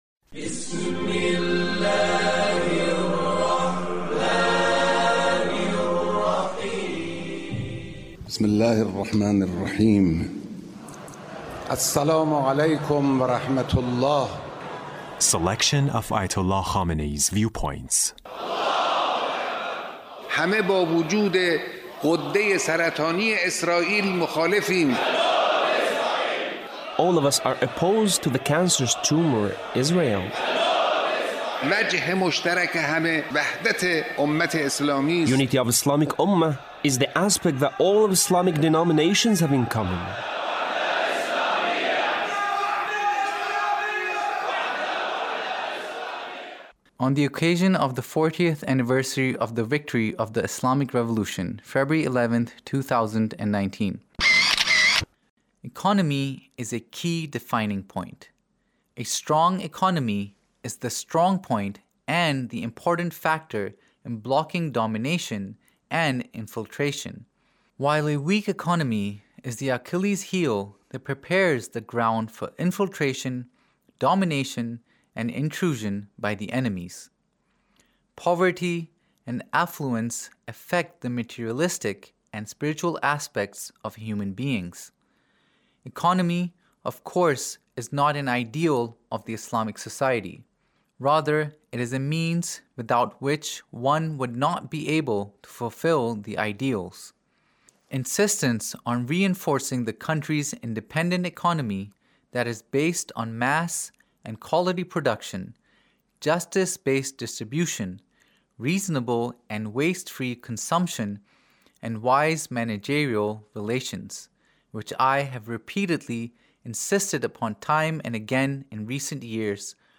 Leader's Speech (1894)